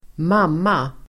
Uttal: [²m'am:a]